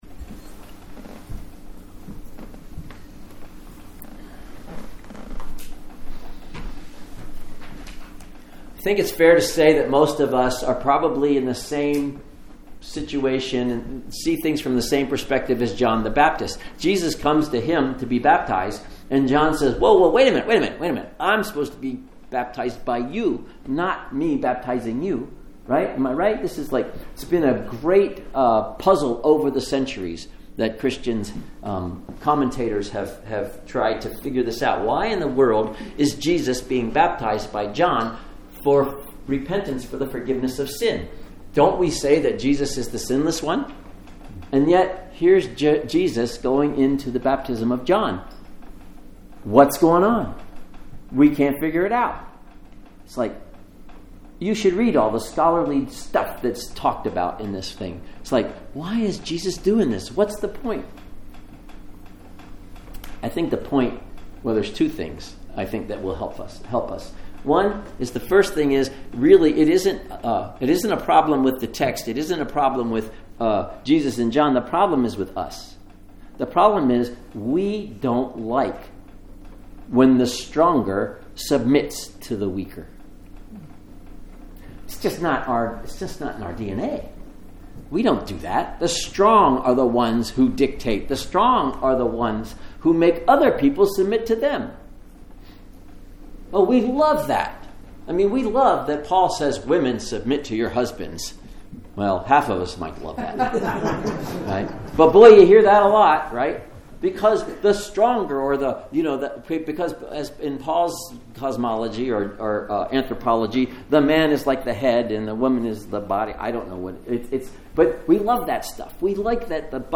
Sermons | Lake Chelan Lutheran Church